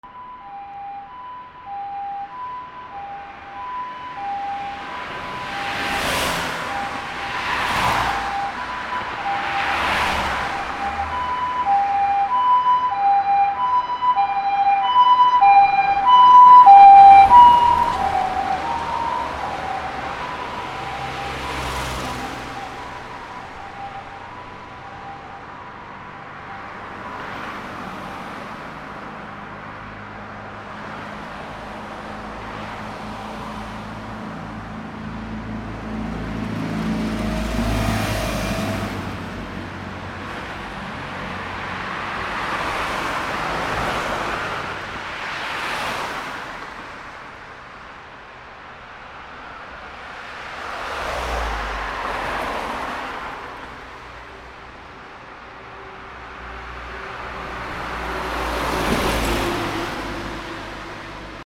/ E｜乗り物 / E-40 ｜救急車・消防車など
救急車 音割れあり